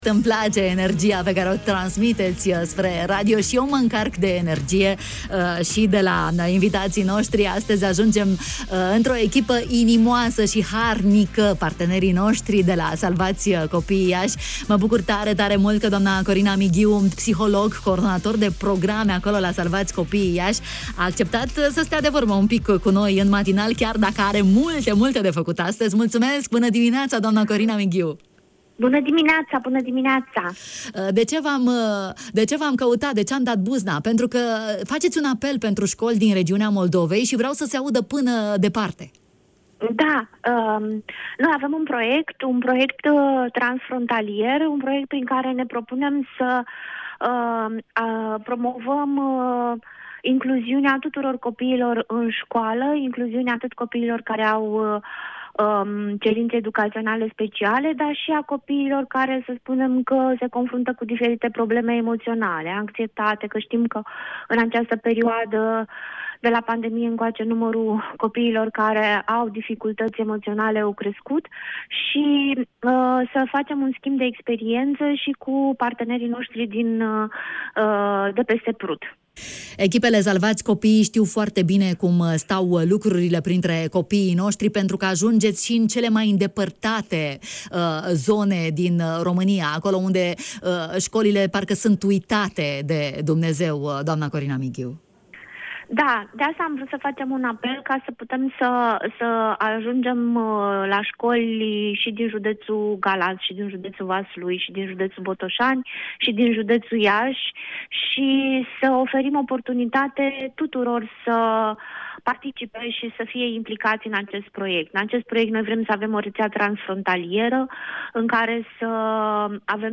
psiholog